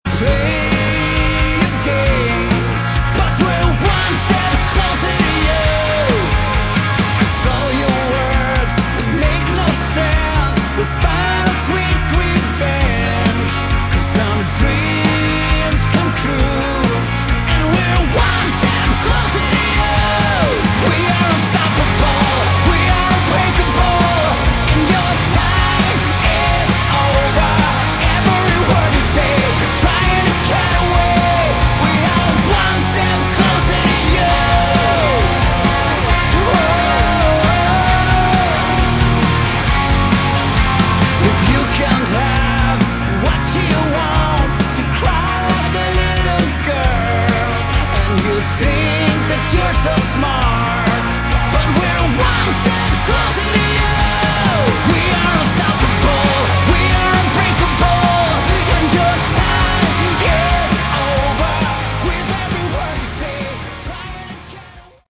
Category: Melodic Hard Rock
vocals
guitars
drums
bass